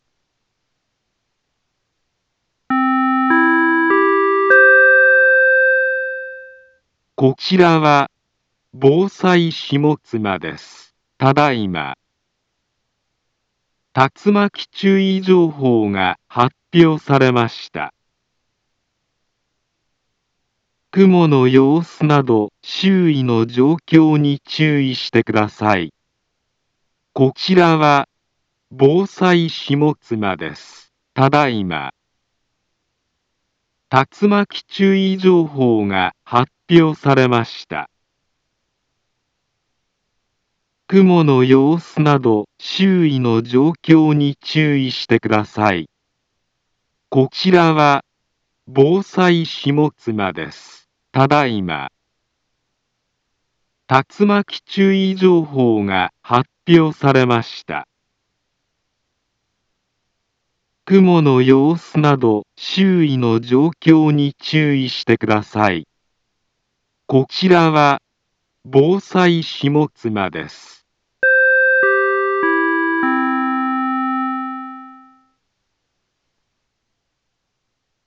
Back Home Ｊアラート情報 音声放送 再生 災害情報 カテゴリ：J-ALERT 登録日時：2024-07-25 18:24:30 インフォメーション：茨城県北部、南部は、竜巻などの激しい突風が発生しやすい気象状況になっています。